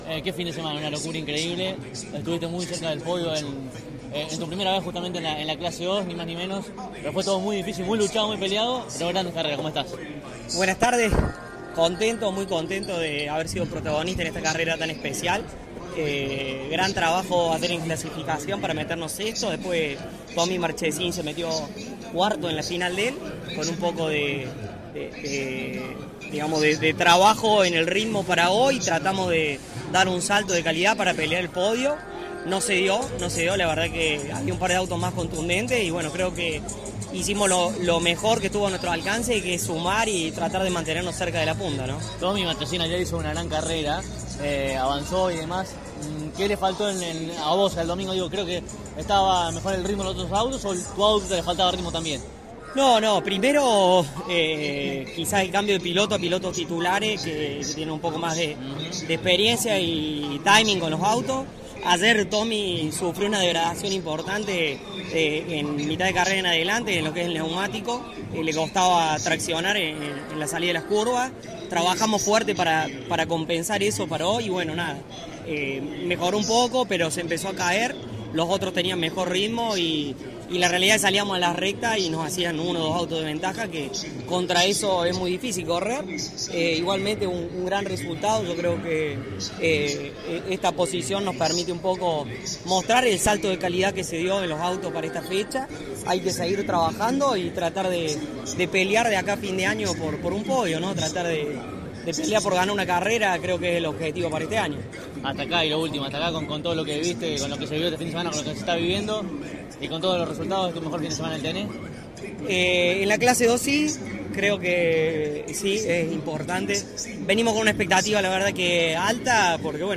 Estas son todas las entrevistas: